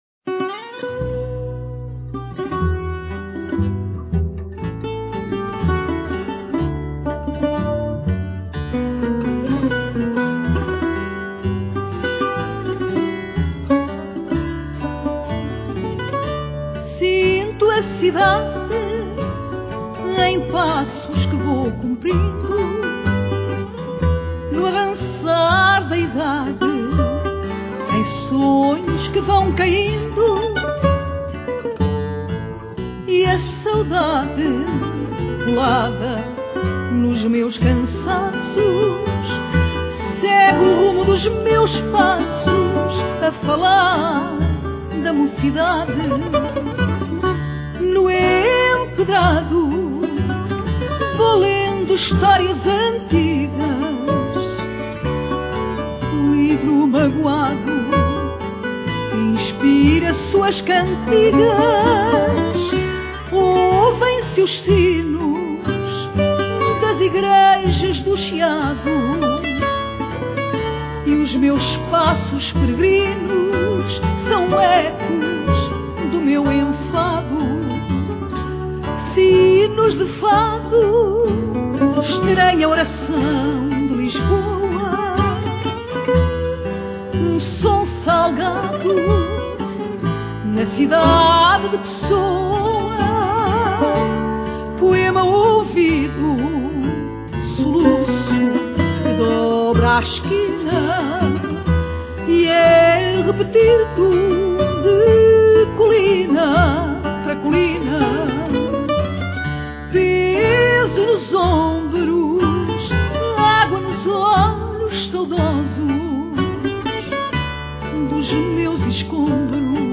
Guitarra
Viola Baixo